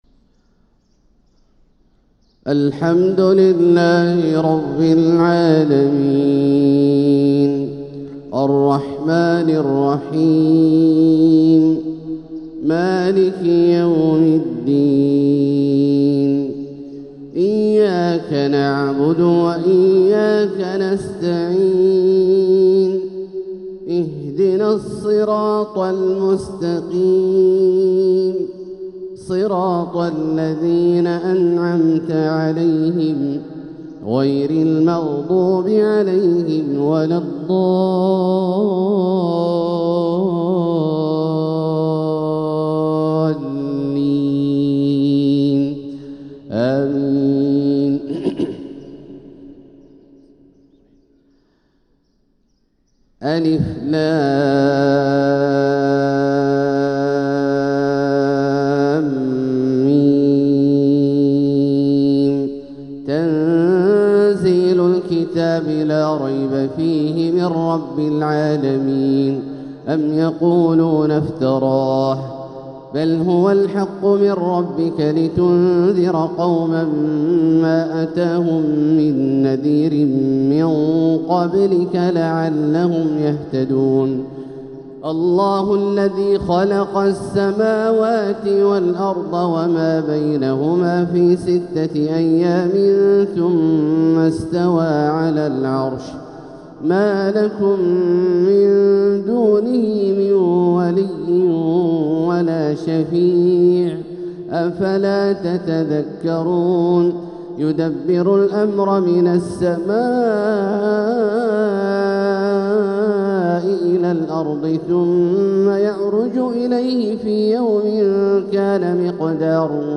سورتي السجدة والإنسان | فجر الجمعة 6-5-1446هـ > ١٤٤٦ هـ > الفروض - تلاوات عبدالله الجهني